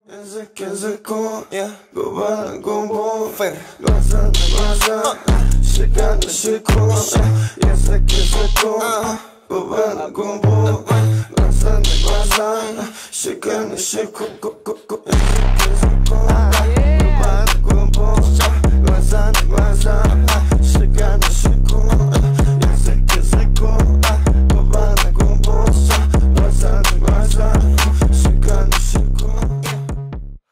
Рэп и Хип Хоп
кавер